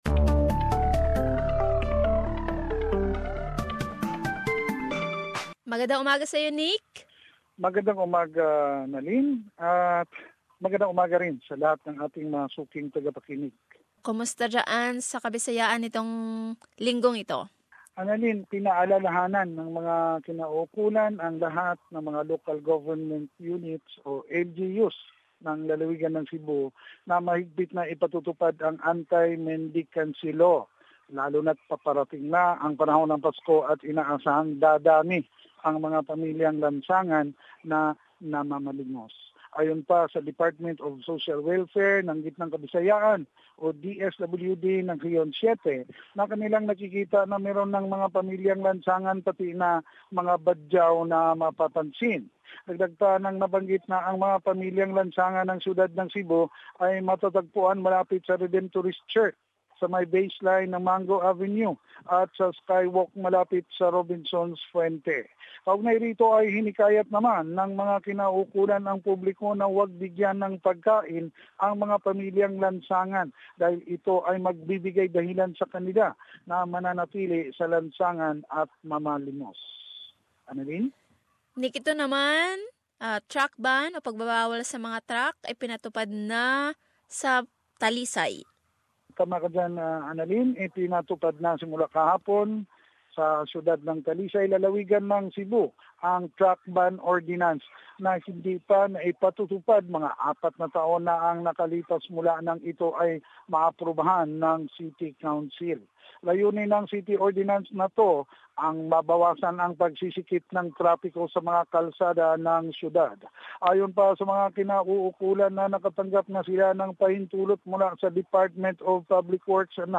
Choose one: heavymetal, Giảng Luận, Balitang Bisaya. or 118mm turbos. Balitang Bisaya.